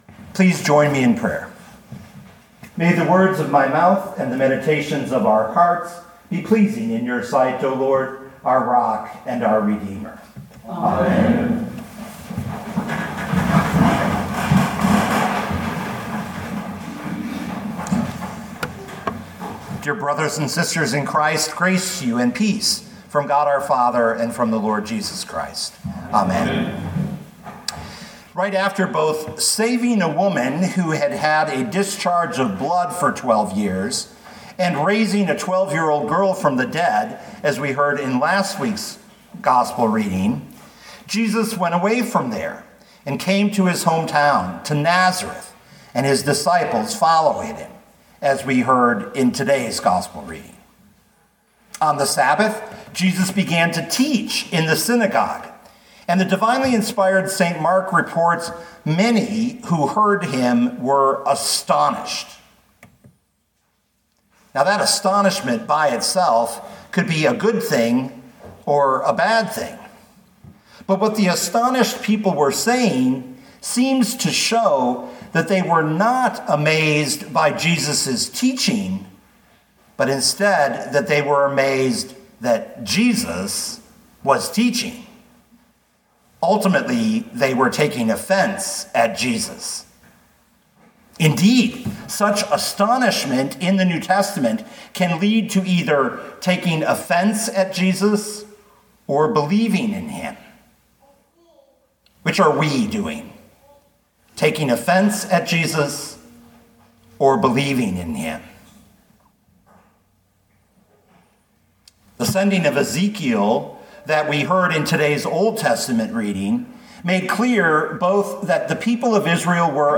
2024 Mark 6:1-13 Listen to the sermon with the player below, or, download the audio.